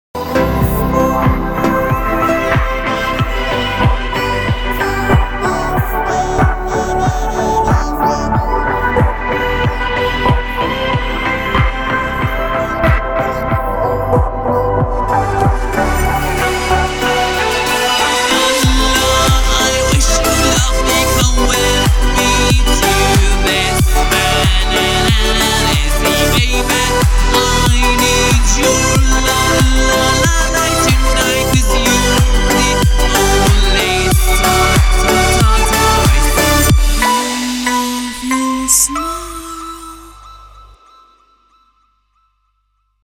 Pop music